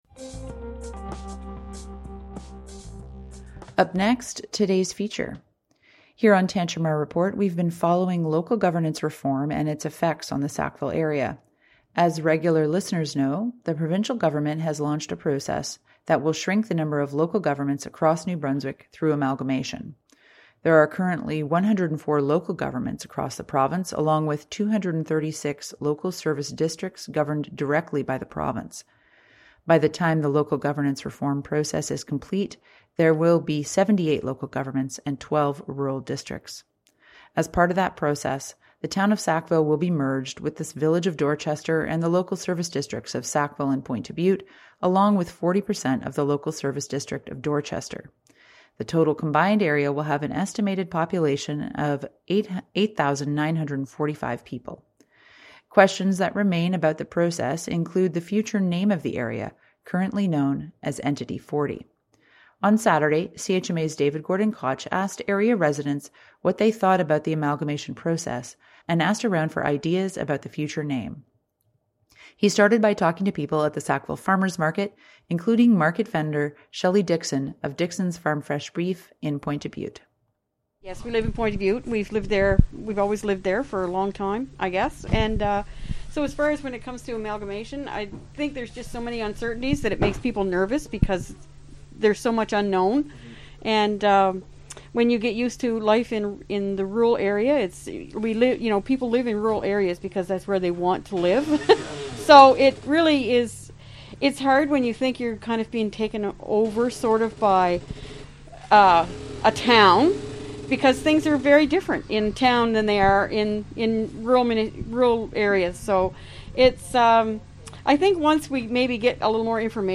CHMA reached out to area residents over the weekend, asking what they think the newly merged area should be called, and how they feel about the amalgamation process so far: Download Audio Prev Previous Post Thousands congregate in Toronto’s march in support of Ukraine Next Post Russo-Ukrainian war cause for concern for Ottawa resident with family in Kyiv Next